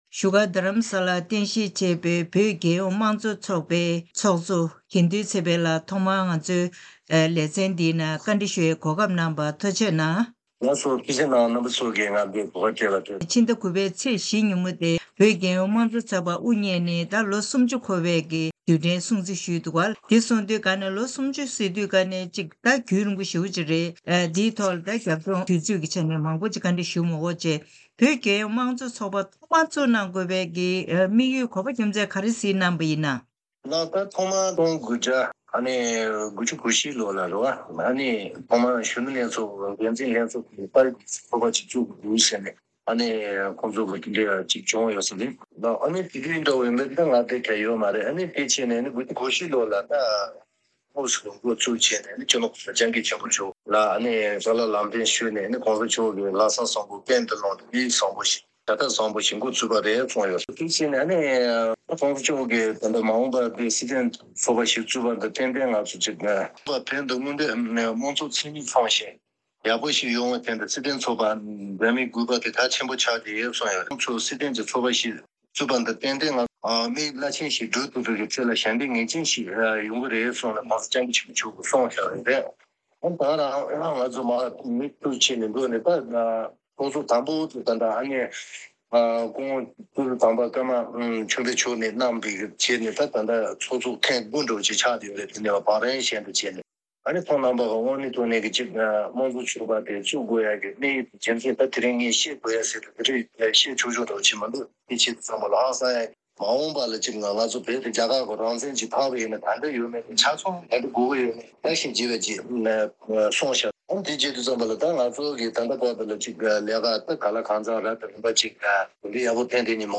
བོད་མི་མང་སྤྱི་འཐུས་ཚོགས་གཙོ་མཆོག་གིས་བོད་རྒྱལ་ཡོངས་མང་གཙོ་ཚོགས་པ་དབུ་བརྙེས་ནས་ལོ་ངོ་སུམ་ཅུ་འཁོར་བའི་དུས་དྲན་སྲུང་བརྩིའི་སྐབས་མང་ཚོགས་ལ་མང་གཙོའི་ལྟ་གྲུབ་གོ་རྟོགས་ཤུགས་ཆེ་ཙམ་སྤེལ་ཐབས་གནང་དགོས་ལུགས་གཏམ་བཤད་གནང་ཡོད་འདུག